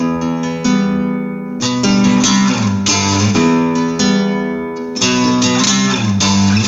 描述：这是一种在尼龙古典吉他上演奏的重复节奏。听起来很干净，这让我想起了一些黑手党世界。
Tag: 尼龙 摇滚 干净 吉他 古典 西班牙吉他